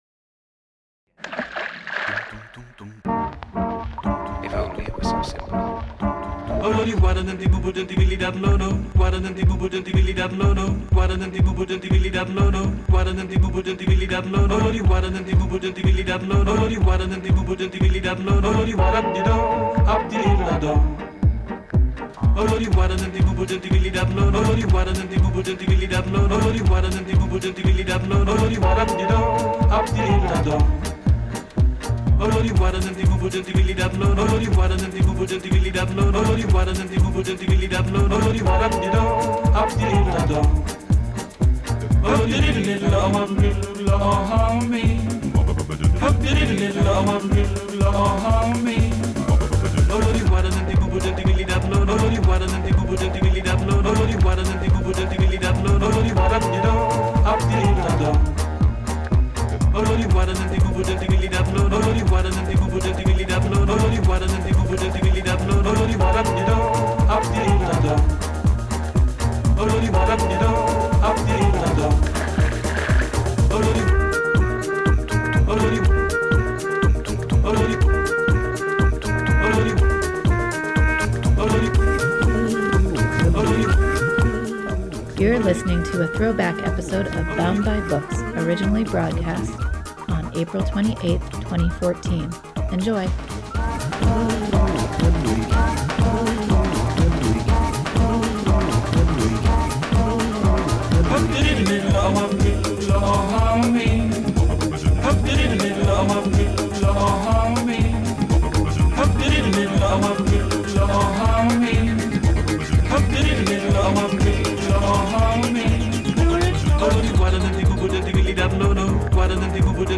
"Bound By Books" is hosted by tweens and teens